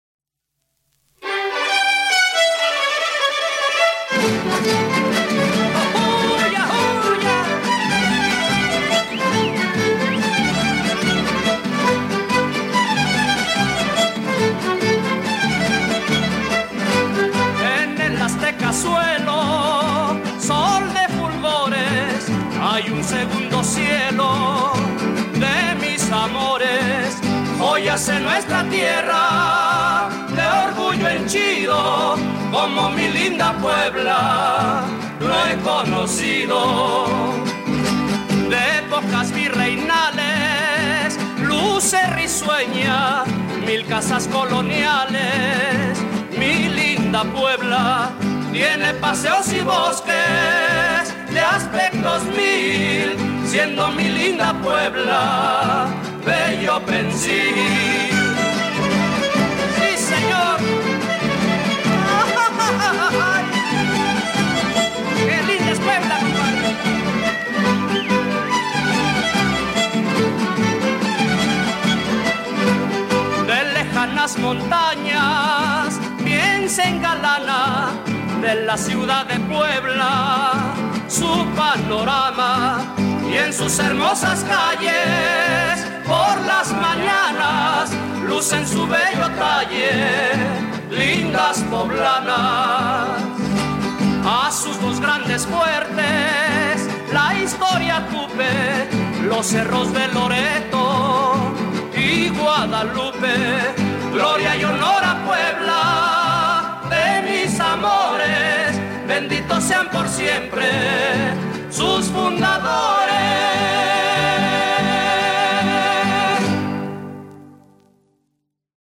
Tópico Música, Música tradicional